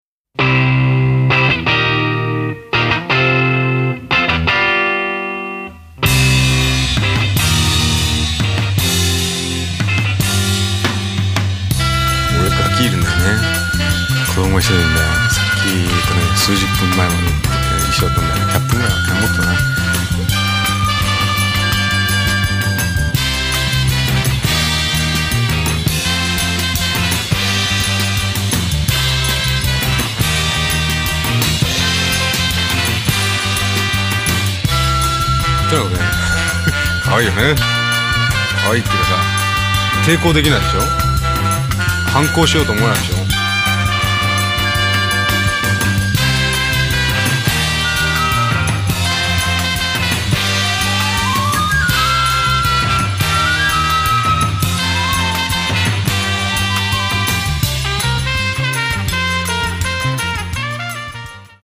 drums
trumpet/flugelhorn
elec.guitar/fork guitar/sitar